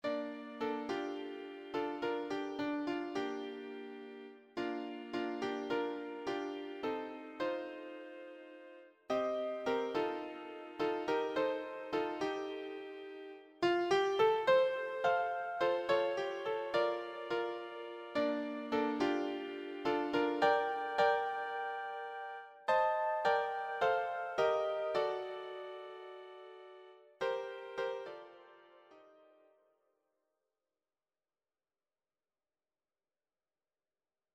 It is therefore quite a cheerful song.